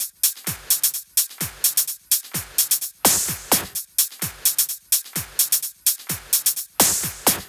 VFH2 128BPM Unimatrix Kit 6.wav